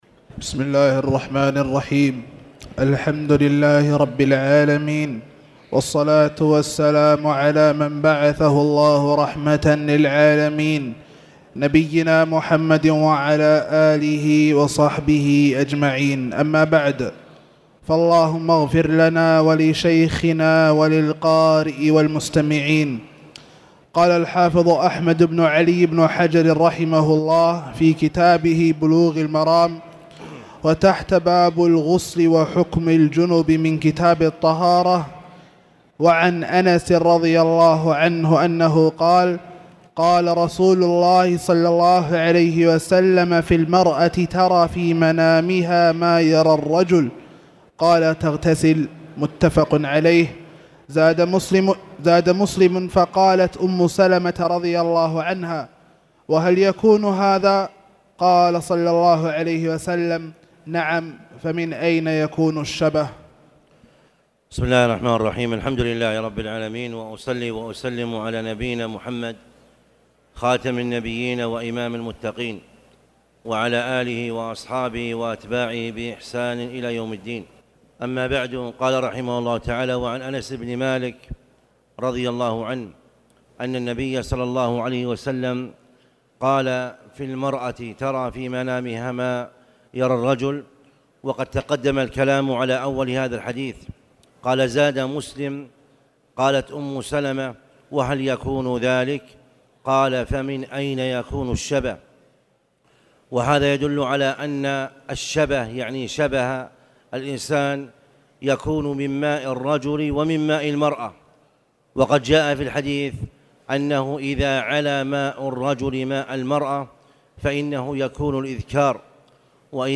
تاريخ النشر ٢٢ ربيع الثاني ١٤٣٨ هـ المكان: المسجد الحرام الشيخ